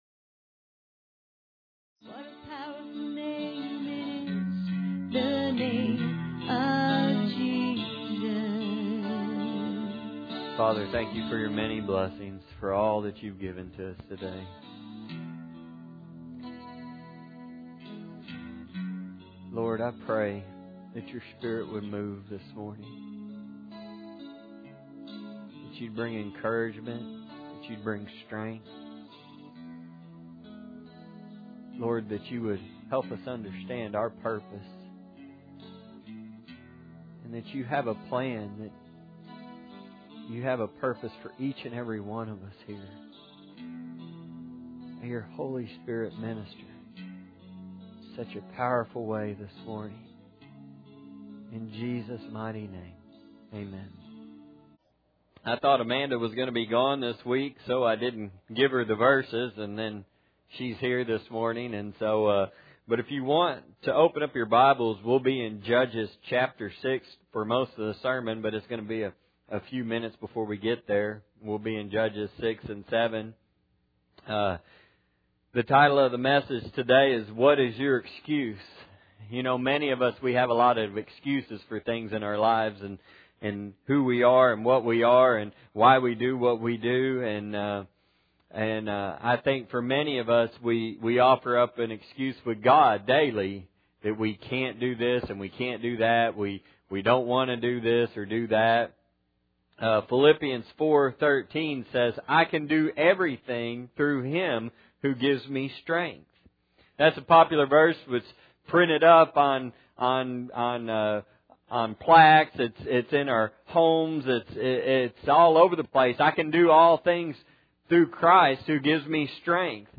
Judges 6 and 7 Service Type: Sunday Morning Bible Text